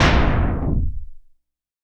LC IMP SLAM 5.WAV